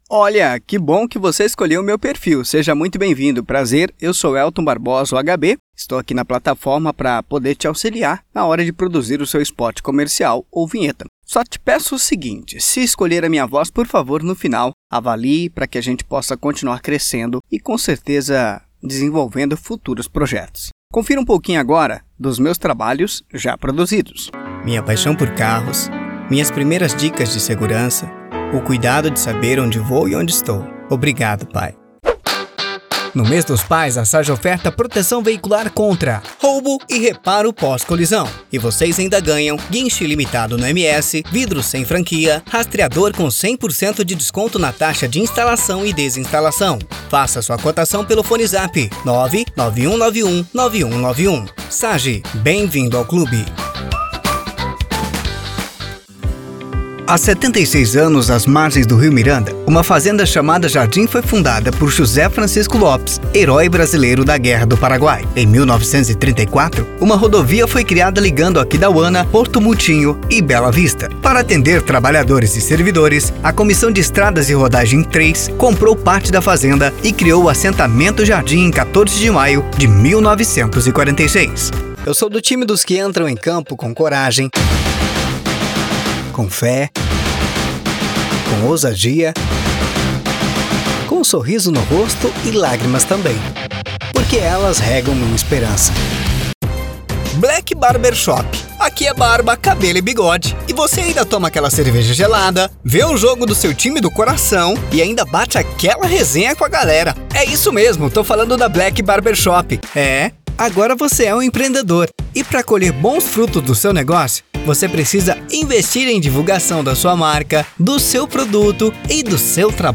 Spot Comercial
Vinhetas
VT Comercial
Impacto
Animada